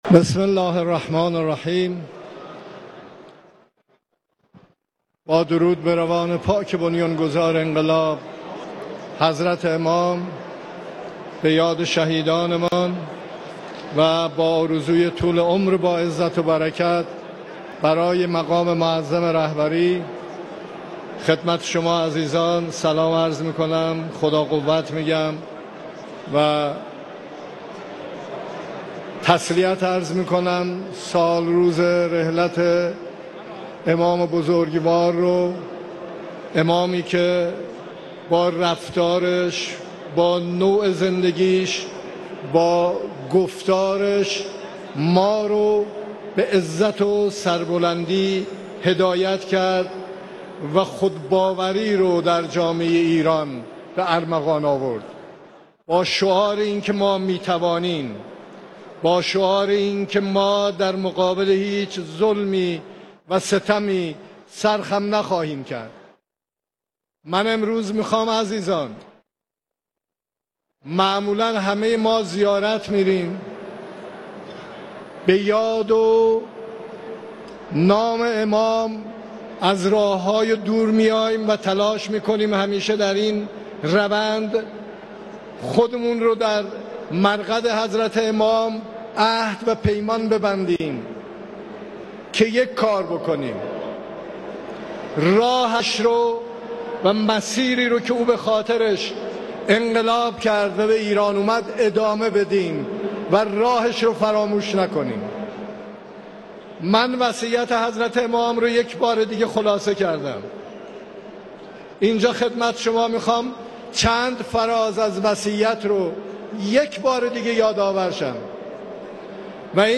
صوت/ سخنان رئیس‌جمهور در مراسم سالگرد ارتحال امام خمینی(ره)
به گزارش مشرق، رئیس جمهور سه شنبه شب در مراسم بزرگداشت سالگرد ارتحال امام خمینی (ره) در حرم امام راحل، با بیان اینکه آنچه امام برای ما به عنوان وصیت به یادگار گذاشته همان چیزی است که خداوند در قرآن مسلمانان را به آن توصیه کرده است، گفت: اگر به گفتار و نصایح امام (ره) عمل کنیم و پایبند باشیم، هیچ قدرتی نخواهد توانست به ما زور بگوید و ما را زمین گیر کند.